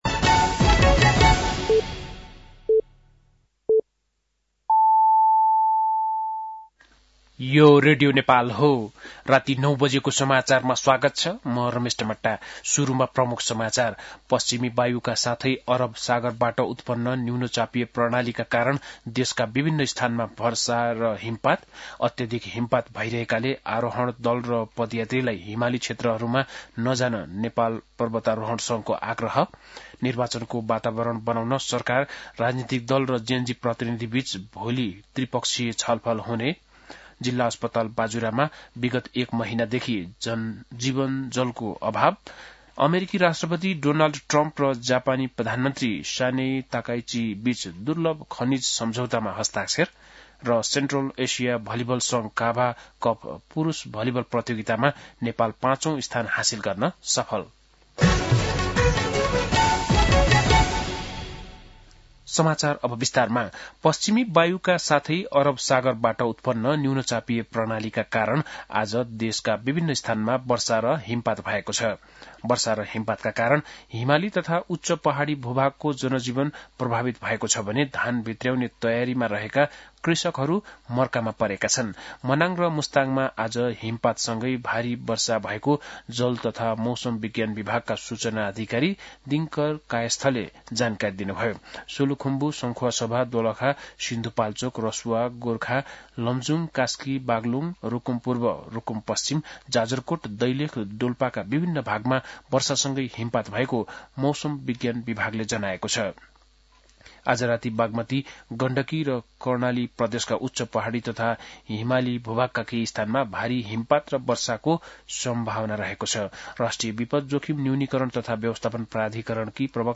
बेलुकी ९ बजेको नेपाली समाचार : ११ कार्तिक , २०८२